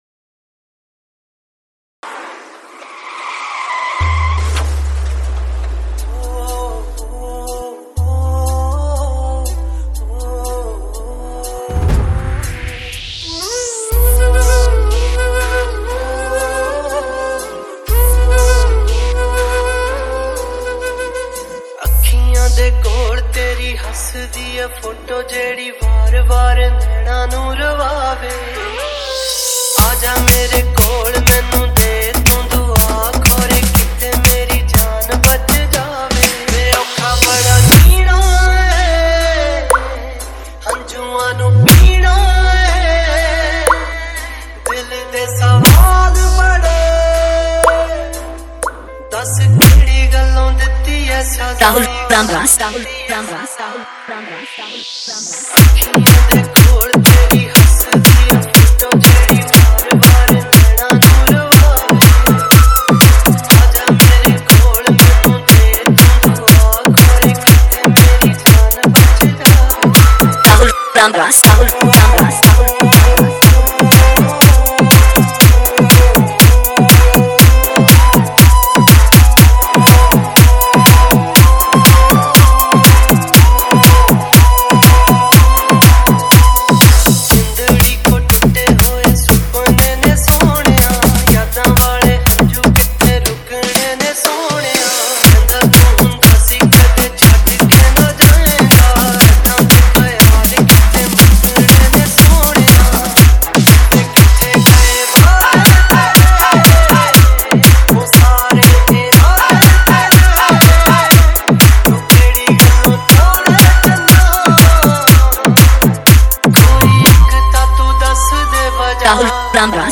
Punjabi Remix